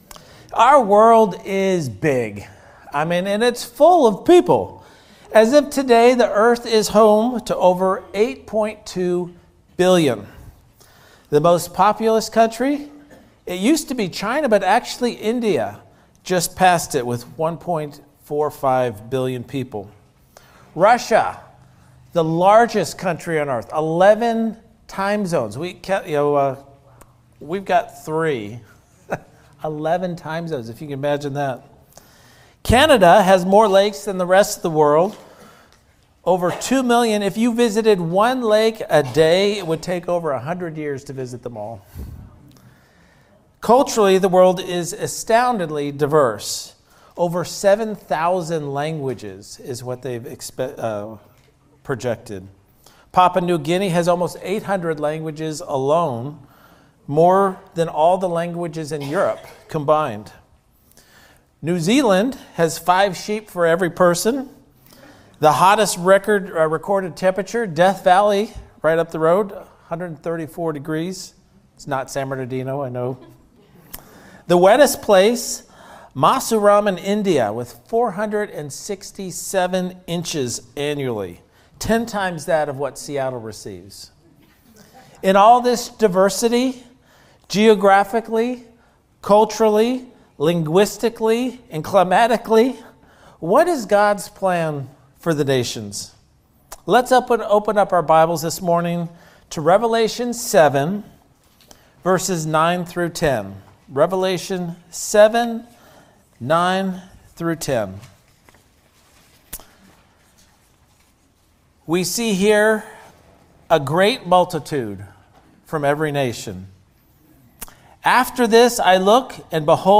Guest Speaker, Standalone Sermon